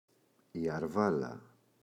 αρβάλα, η [a’rvala]